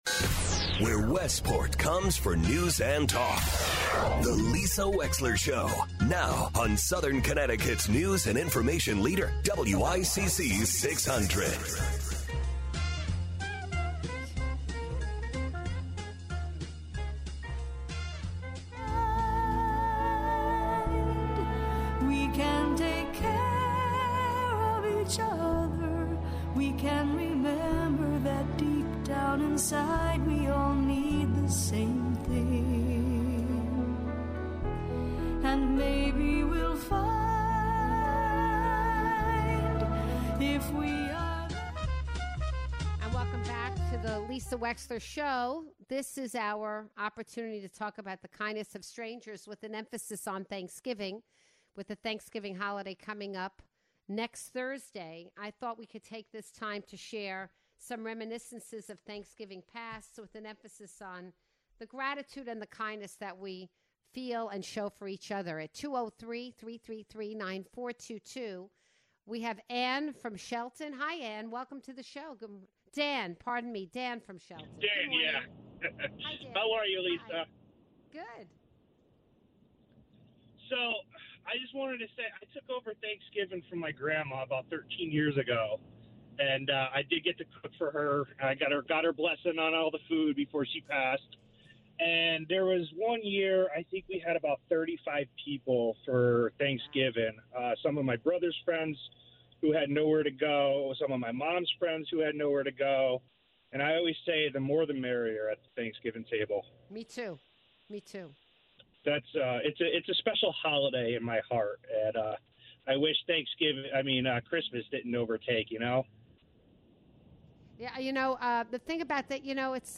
takes your calls